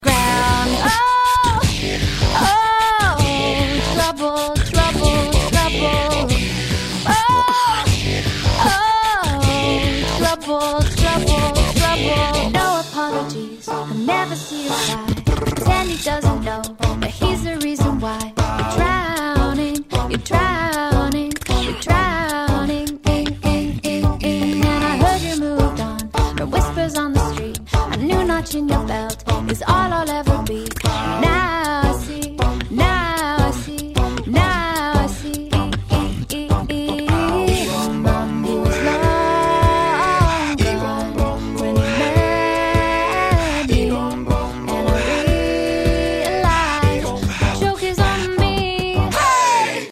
Прикольный Битбокс